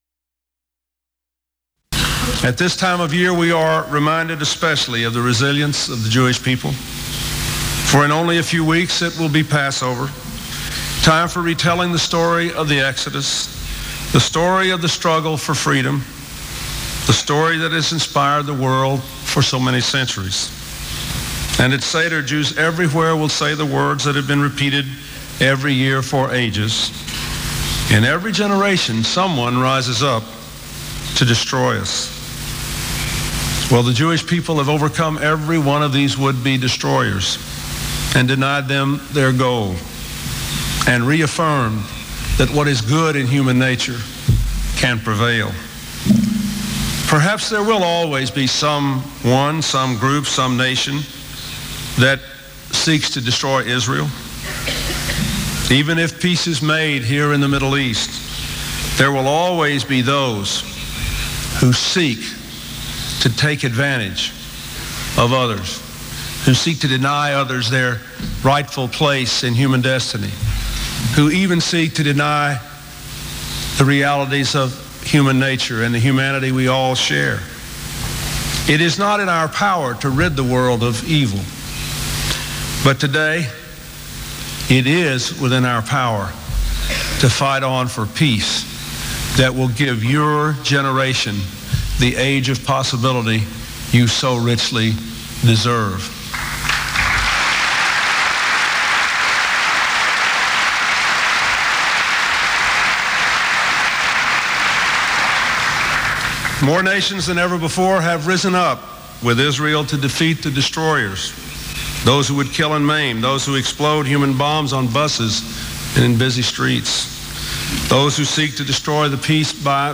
U.S. President Bill Clinton addresses the people of Israel from Tel Aviv following the anti-terrorism conference in Sharm el-Sheikh
Broadcast on CNN TV News Special, Mar. 14, 1996.